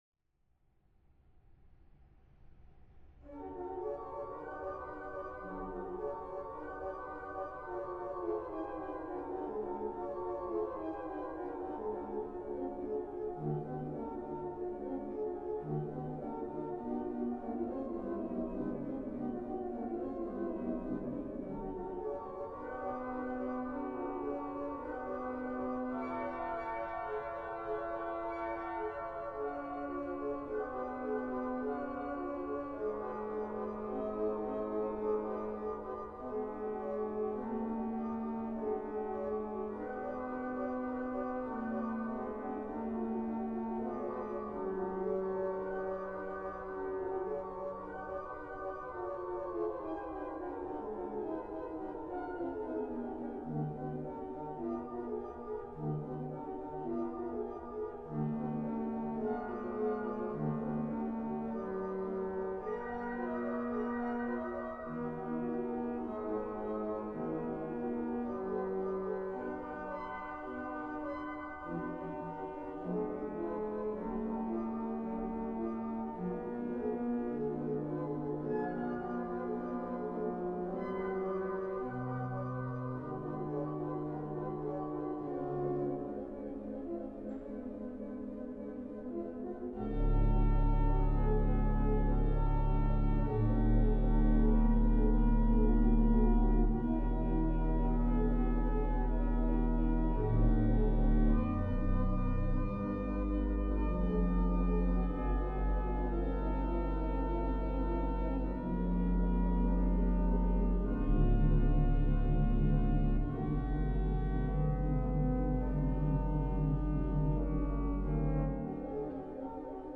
Organo romantico sinfonico storico francese
La = 435 Hz
Temperamento equabile